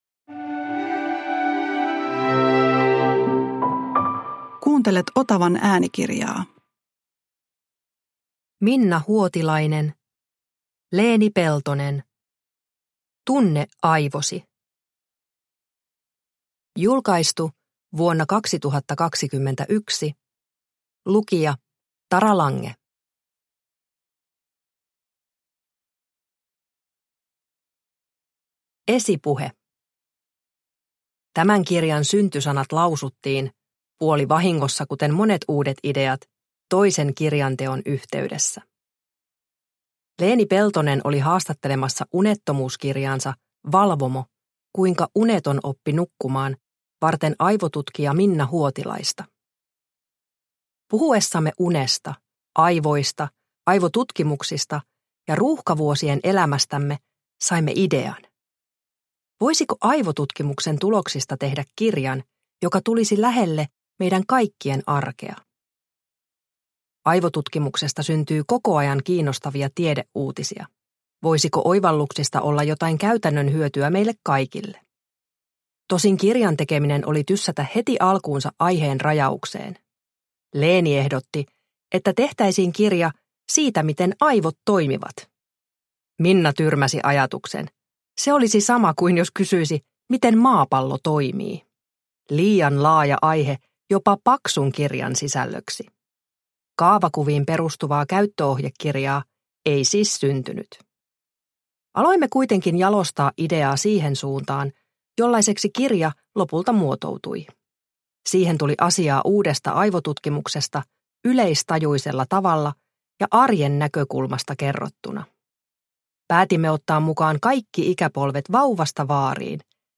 Tunne aivosi – Ljudbok – Laddas ner